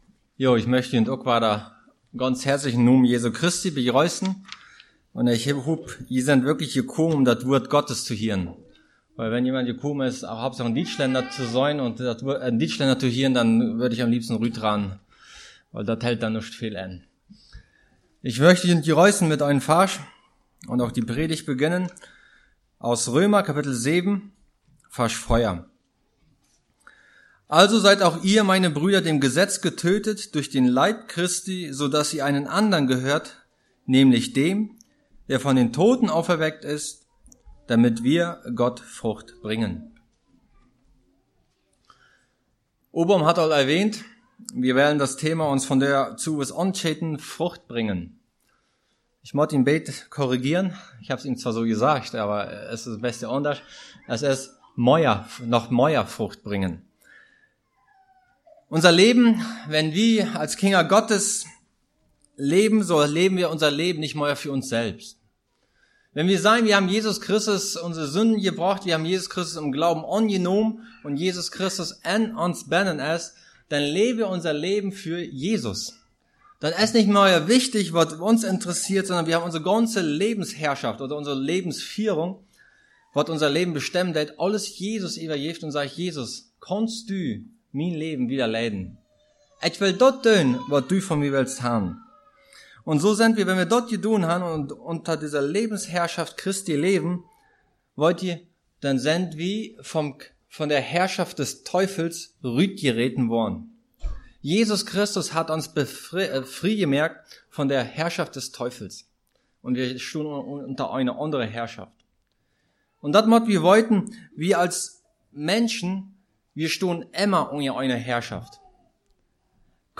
message brought on Dec. 21, 2018